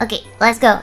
Worms speechbanks
Fire.wav